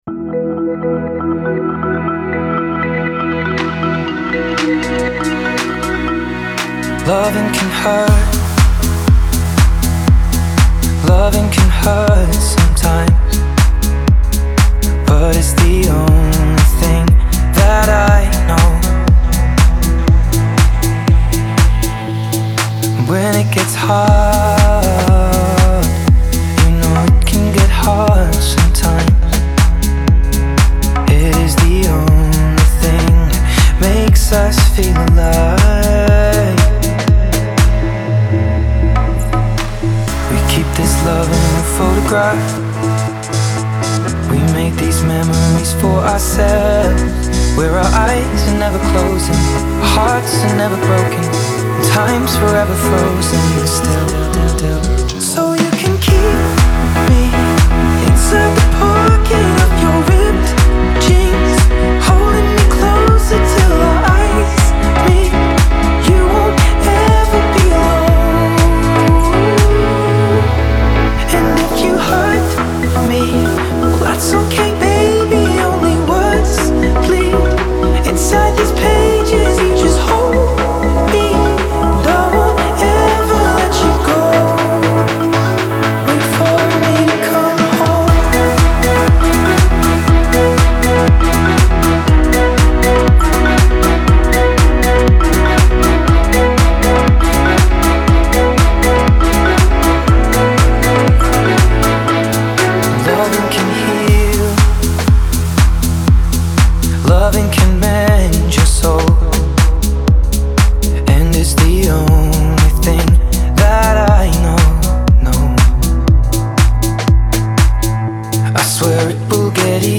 Категория: Deep House